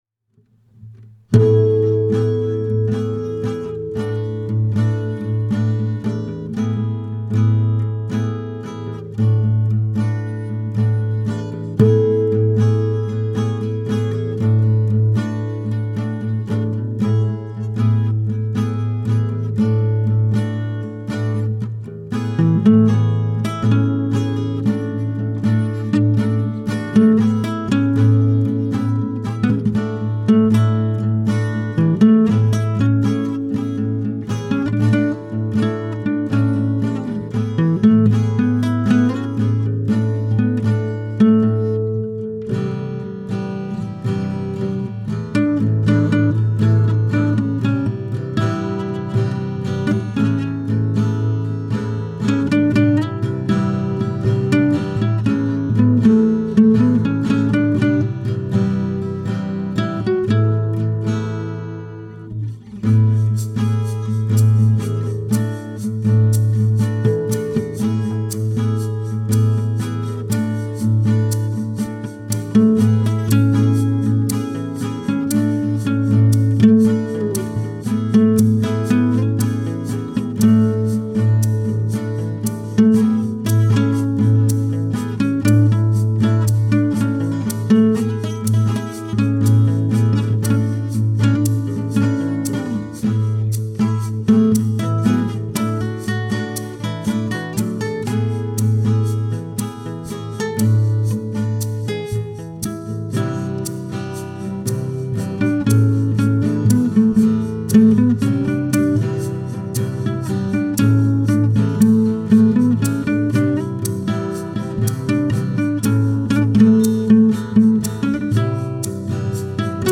I guess my goal was to make this “three acoustic guitars and a shaker” tune nice and relaxing enough that it overrides the fact that it’s in 5/4 time.
Instruments, production, mixing by me. From The Baseball Project.